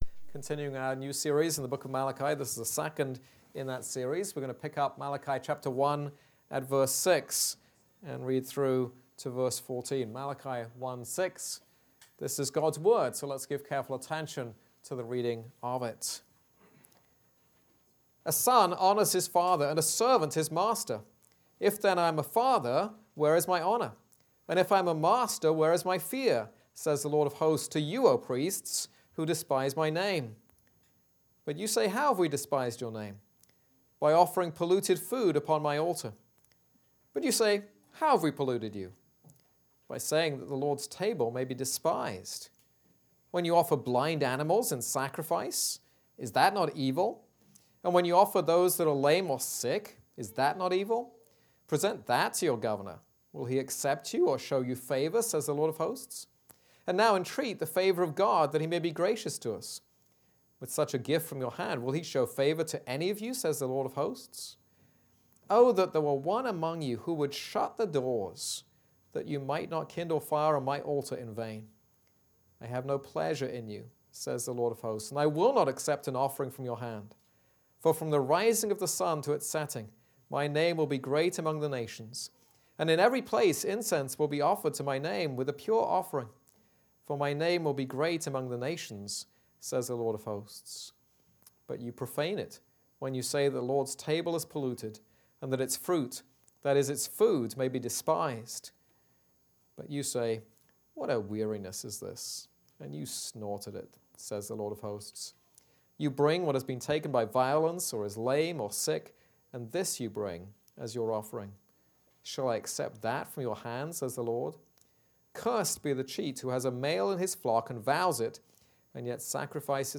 This is a sermon on Malachi 1:6-14.